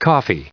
coffee_en-us_recite_stardict.mp3